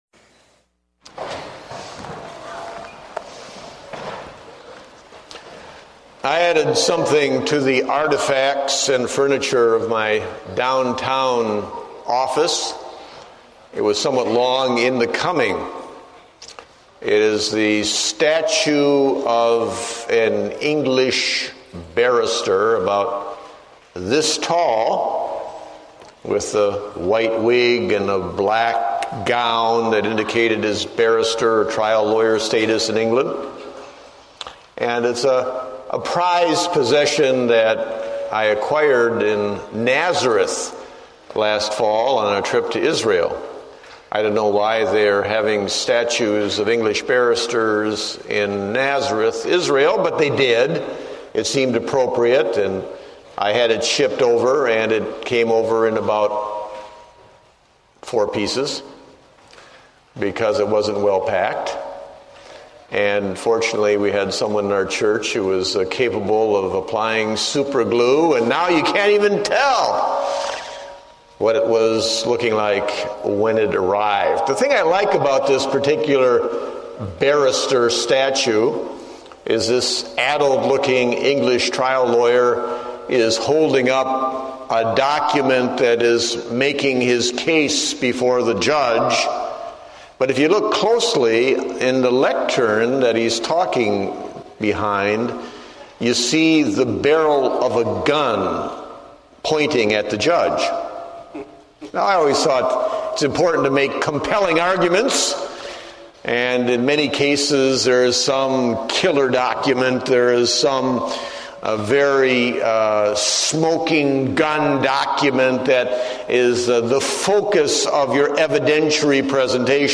Date: June 29, 2008 (Morning Service)